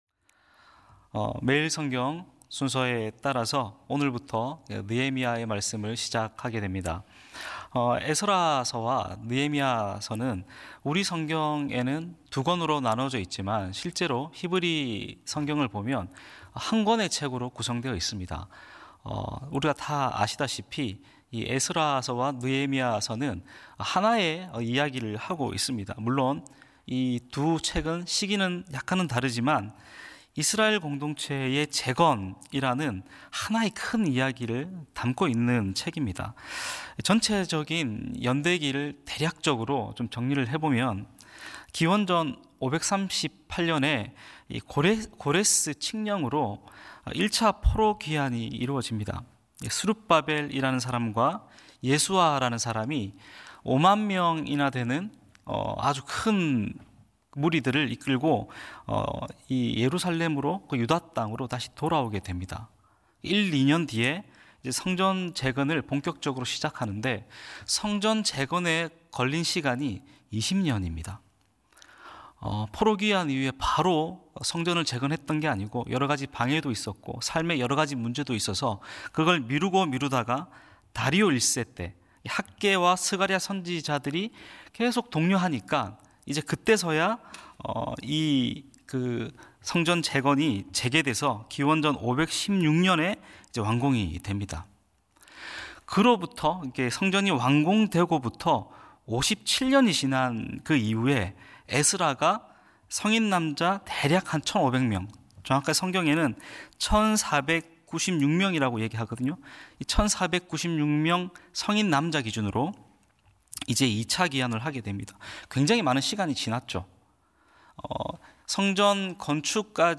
새벽예배